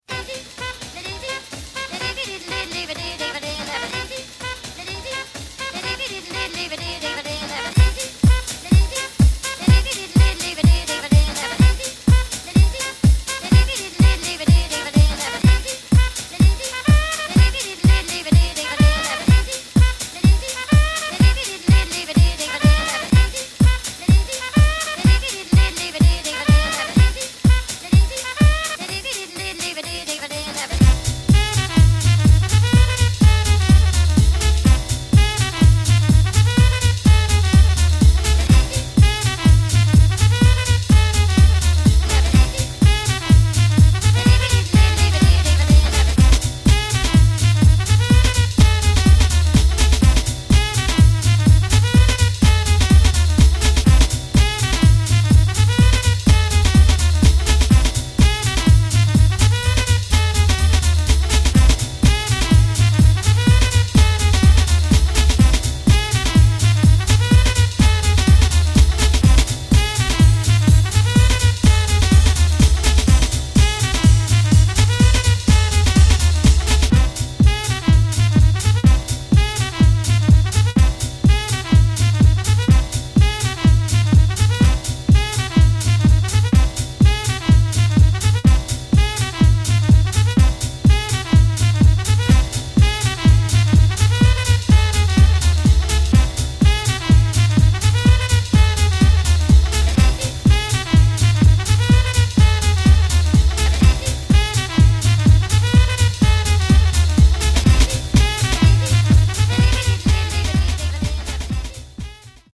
funky house tracks